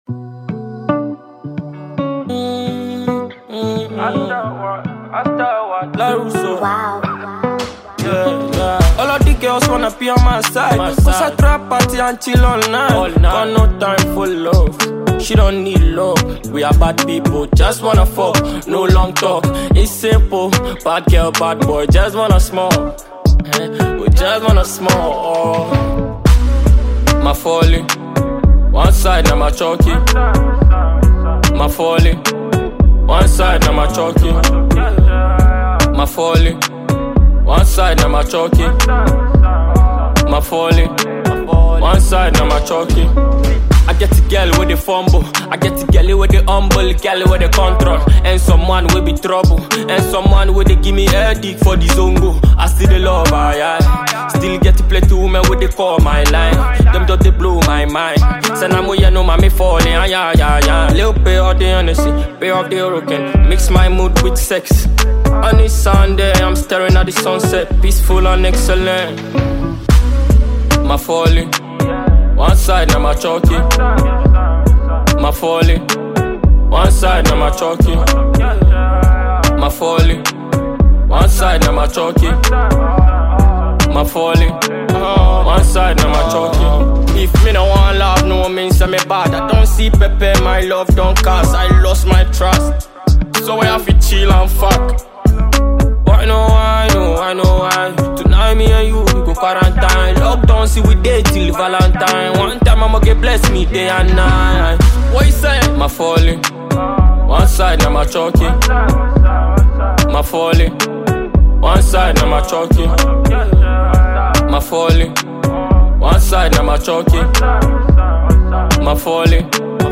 Ghanaian reggae dancehall artist